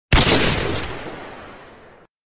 Gunshot